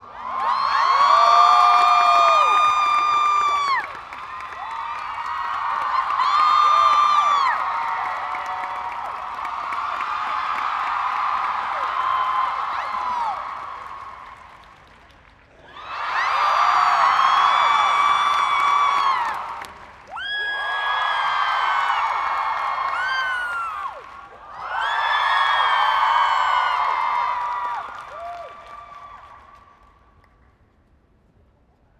cheer1.wav